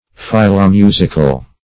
Philomusical \Phil`o*mu"sic*al\